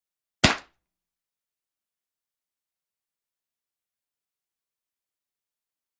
Paint ball Guns; Single Fire.